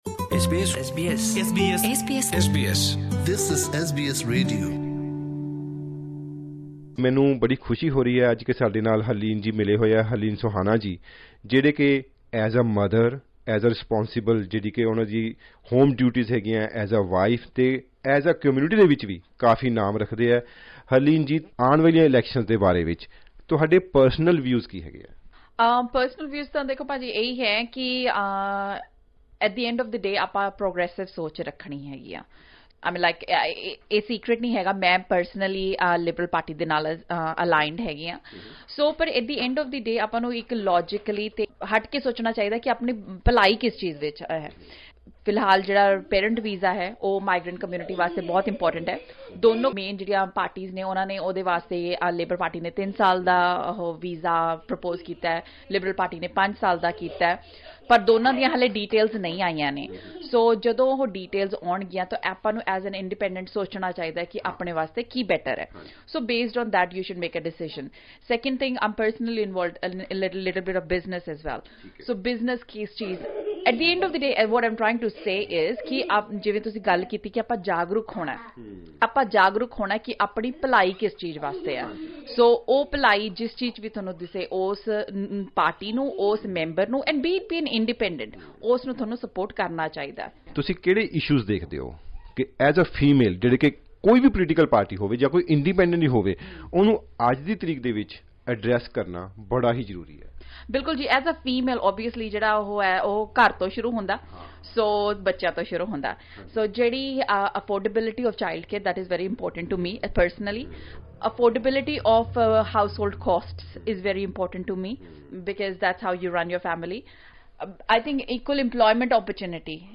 Vox Pops - What Sydney voters think of coming elections